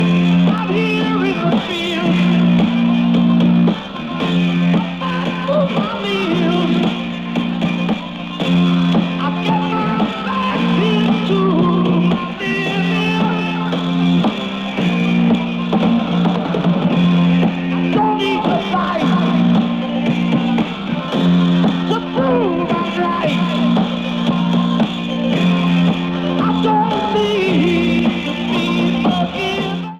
Live versions from Paris France, September 9, 1972.
Sound Samples/Track Listing (All Tracks In Mono)